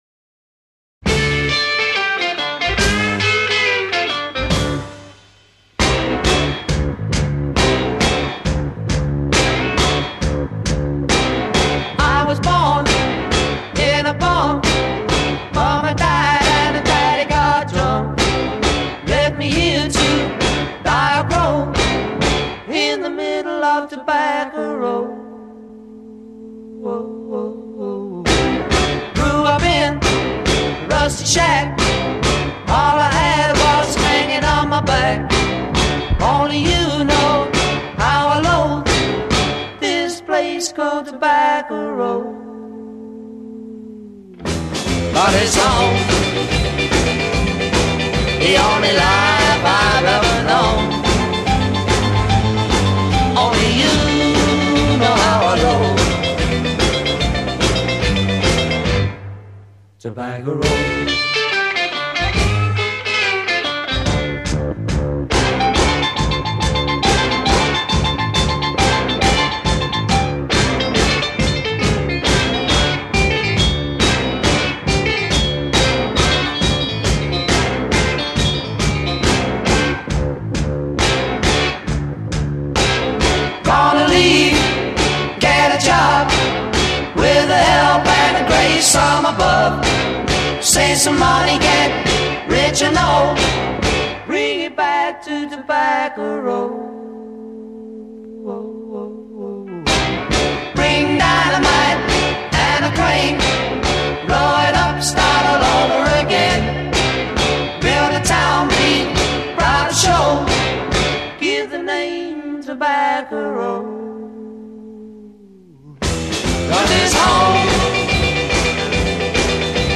Recorded at IBC Studios, London; May 1964.
Intro 0:00 12 Guitar/piano solo against bass & drums.
Pause and begin bass driven beat.
A Verse : 19 Unison vocals on each to 2-part harmony.
Verse ends in free-time harmony.   a
A Verse : 22 Piano solo
A Verse : 19 Add echo to vocals.
Outro   12+ Repeat bass riff and fade